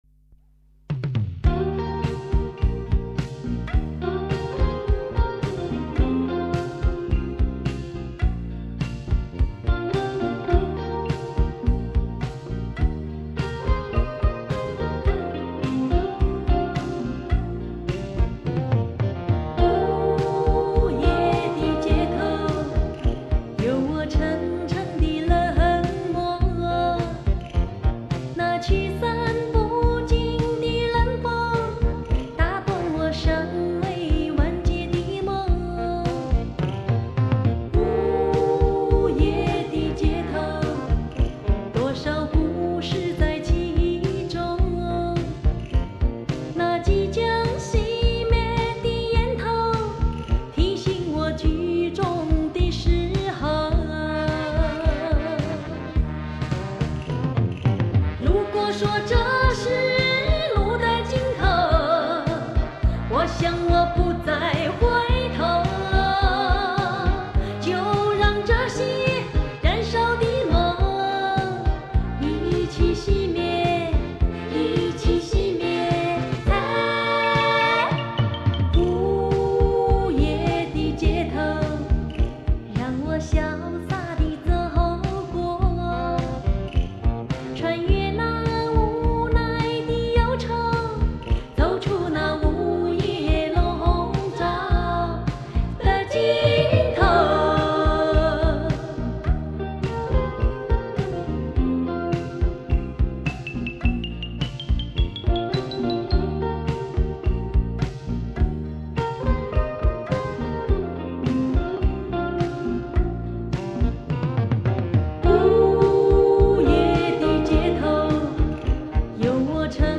小调　小曲类型的歌曲。